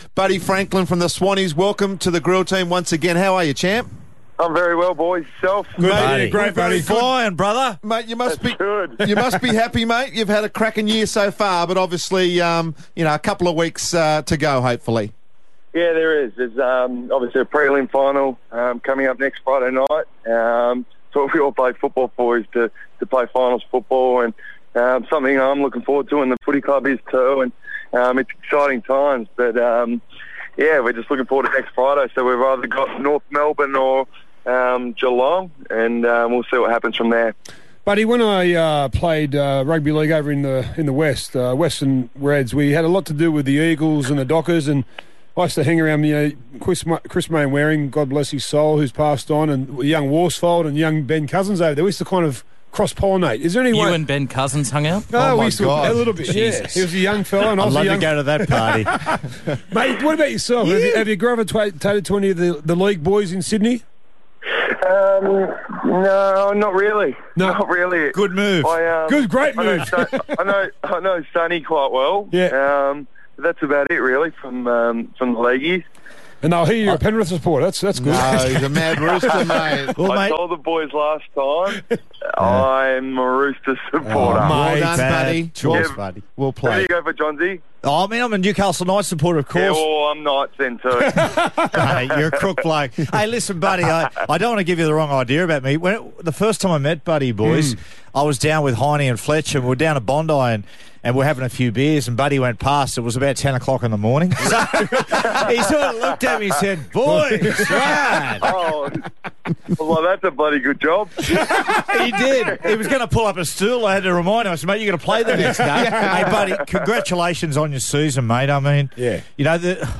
Swans forward Lance Franklin appeared on Triple M Sydney's Grill Team program on Thursday September 11, 2014